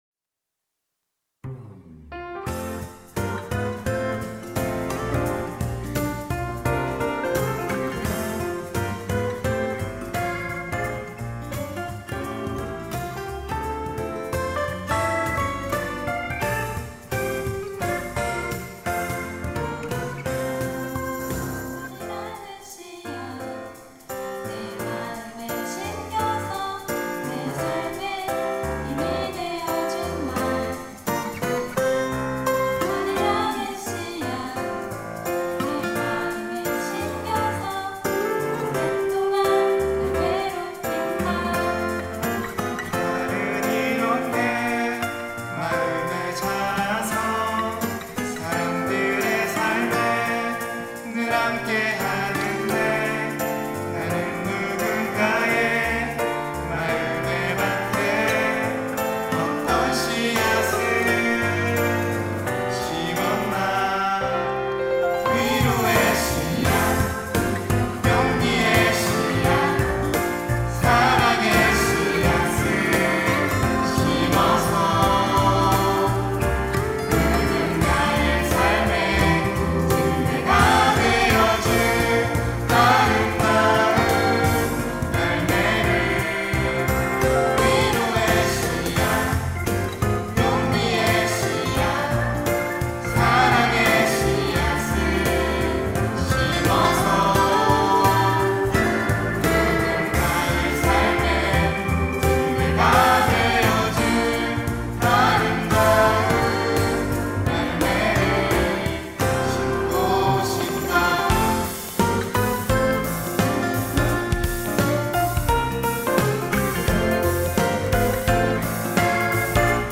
특송과 특주 - 아름다운 씨앗
청년부 교육팀